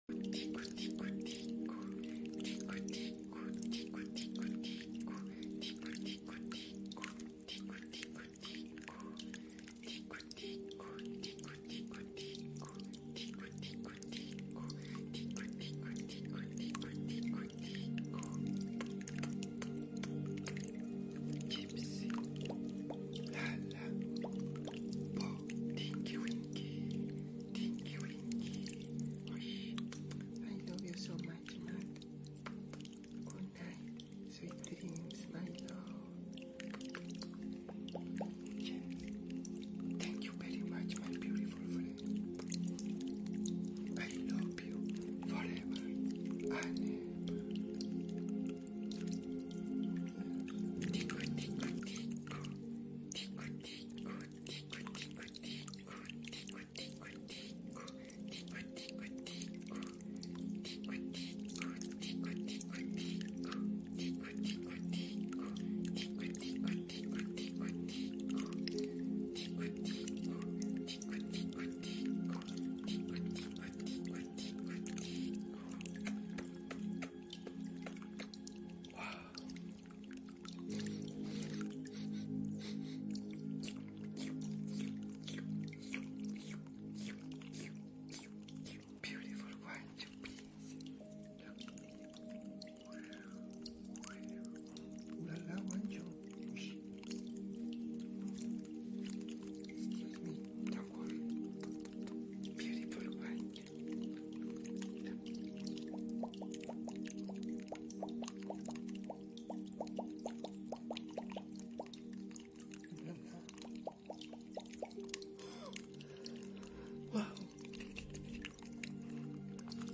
ASMR To Relax After A Sound Effects Free Download